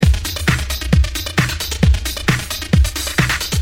hooj_102_slow_loop.mp3